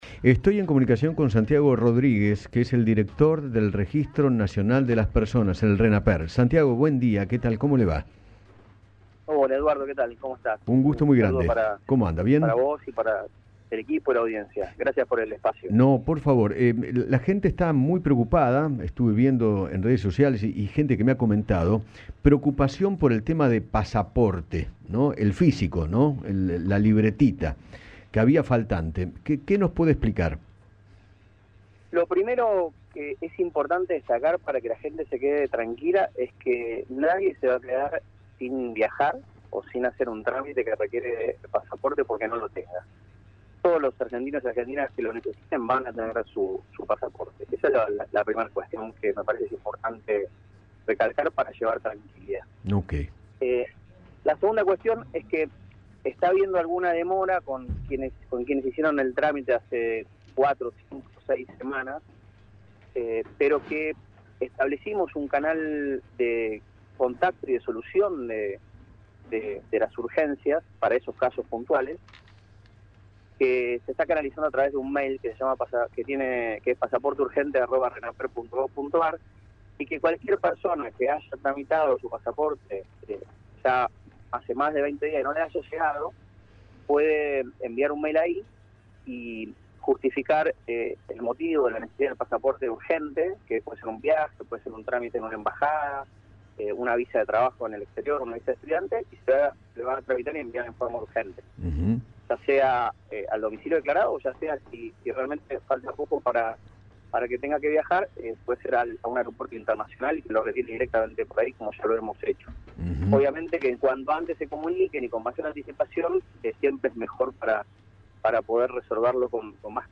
Santiago Rodríguez, director del Renaper, dialogó con Eduardo Feinmann sobre el valor de los distintos tipos de pasaporte y detalló cuánto tarda dicho trámite.
Cuanto-cuesta-sacar-el-pasaporte-Radio-Rivadavia-AM630.mp3